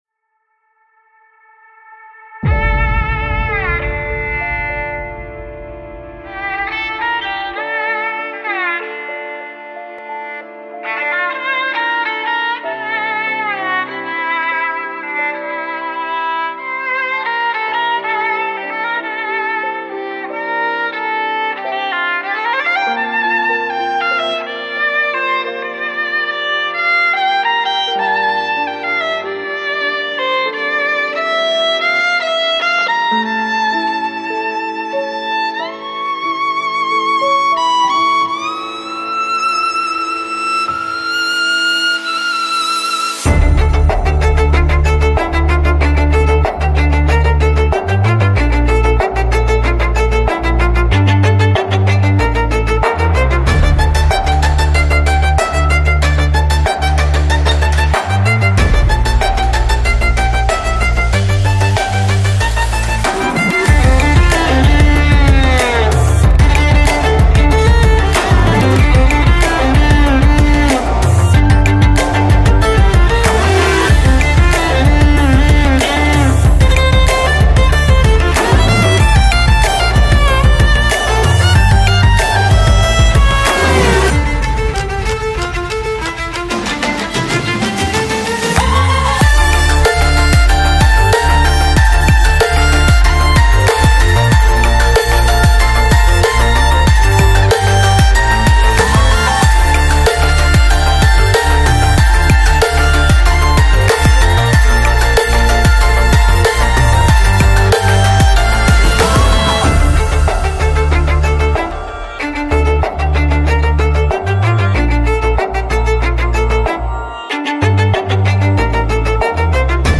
موسیقی اینسترومنتال موسیقی بیکلام